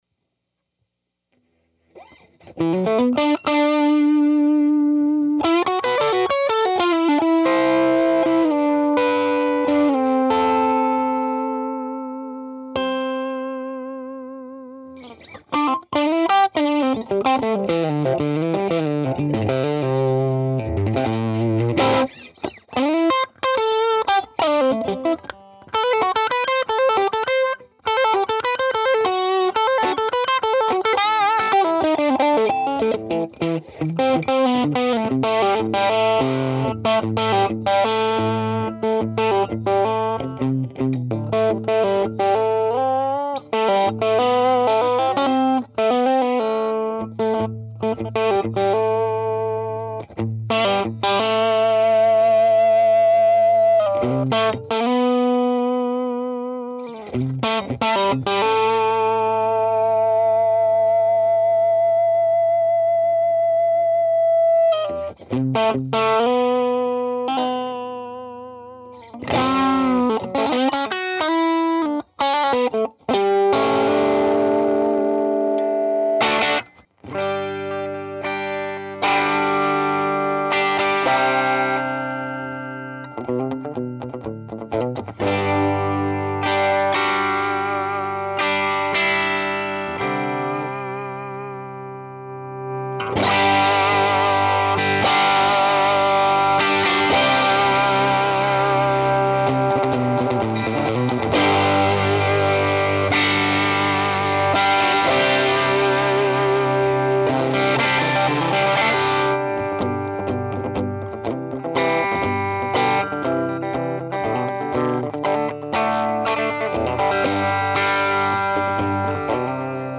These amps usually break into pretty good power tube distortion as you turn them up.
Dirty
Trinity_deluxe_dirty_ strat.mp3